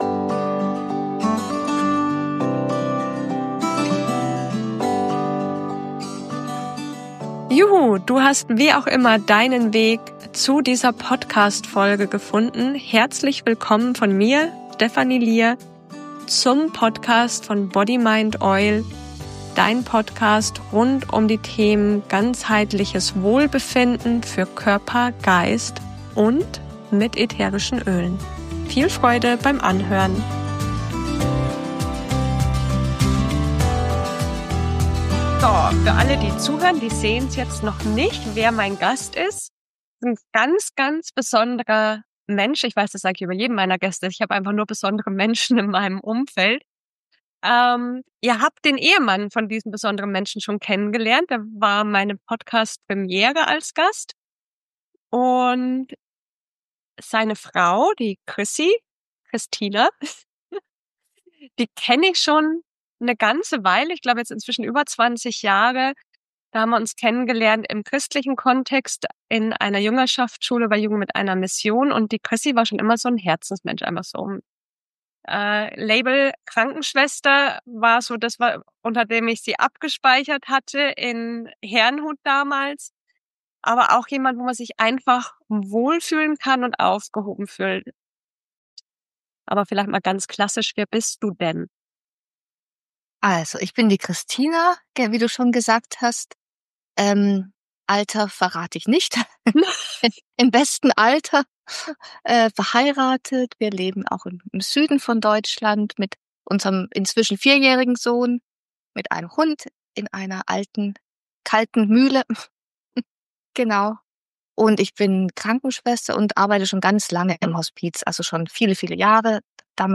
Ein Gespräch über Loslassen, Übergänge, ätherische Öle – und die Magie des gegenwärtigen Moments.